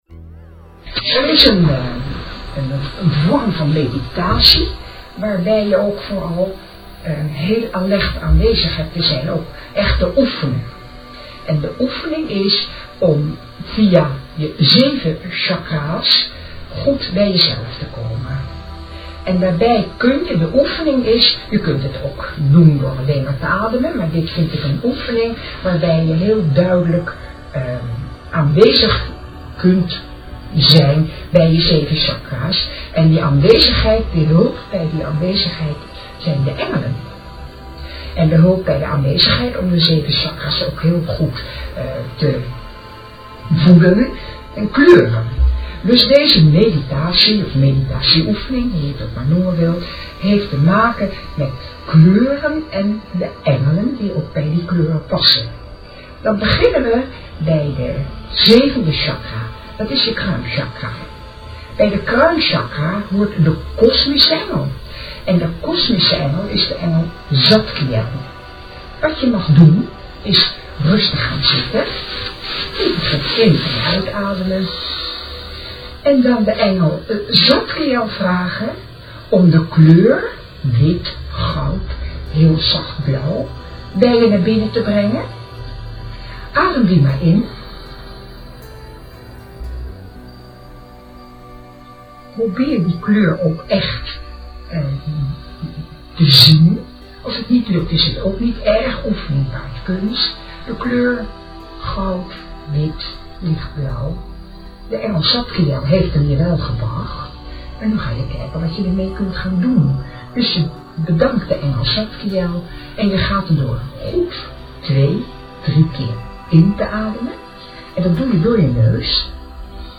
Vandaag heb ik bij mijn natuurarts een hele krachtige en helende meditatie opgenomen die alle engelen, alle kleuren en alle chakra's oproept.
engelen_meditatie_complete.mp3